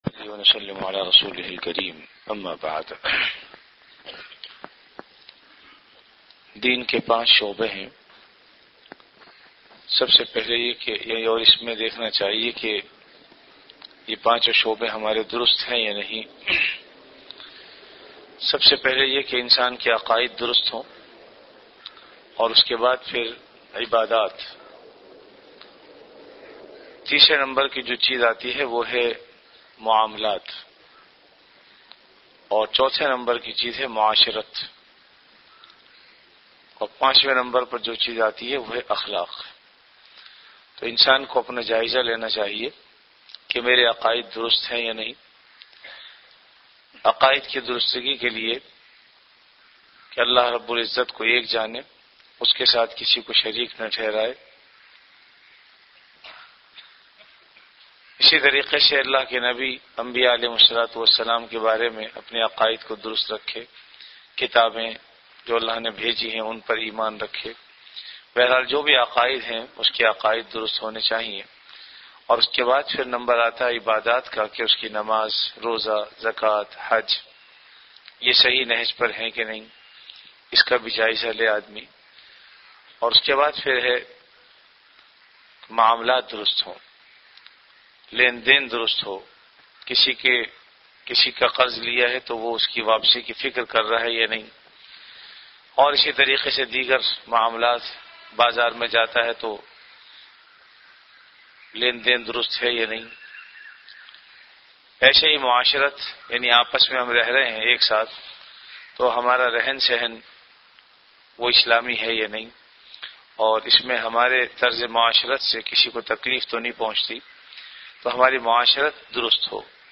Ramadan - Dars-e-Hadees · Jamia Masjid Bait-ul-Mukkaram, Karachi